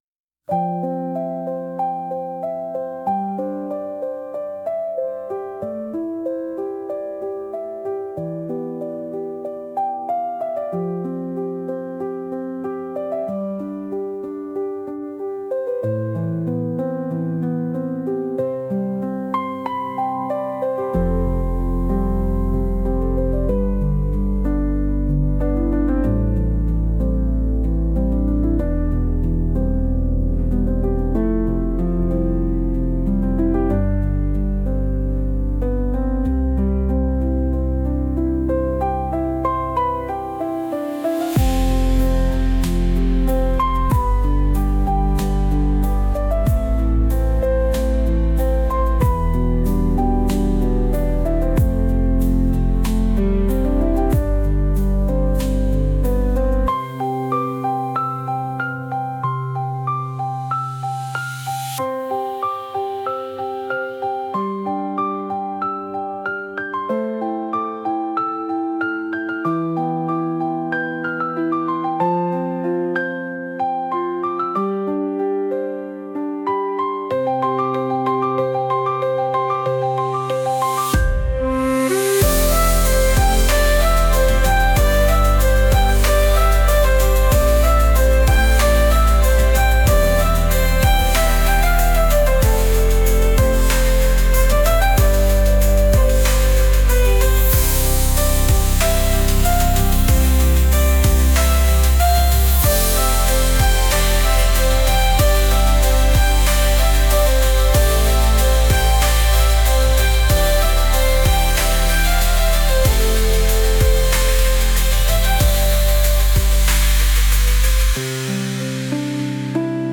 たいしたことじゃないけど悩んでいるときのBGM